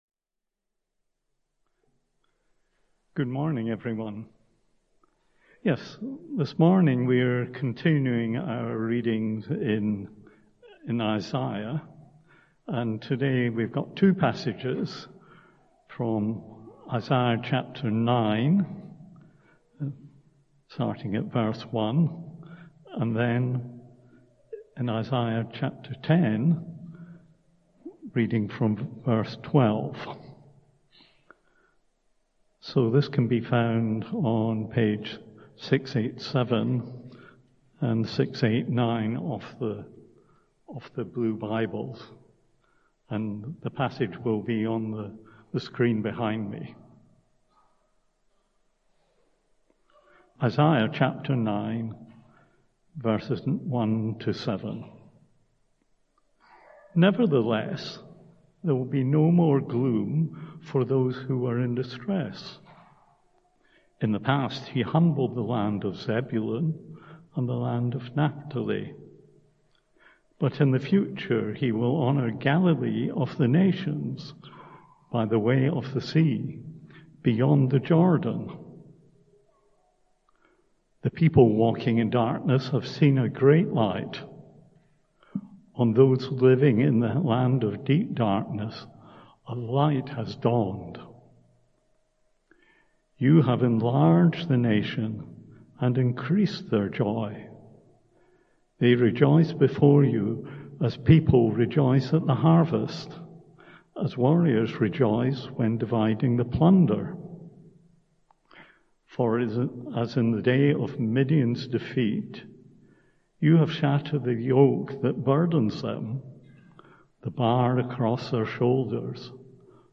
Isaiah 9:1-7; 10:12-16 Talk Outline The talk begins by reflecting on the joy and anticipation that Christmas brings, even when it appears early in October with decorations and festive treats.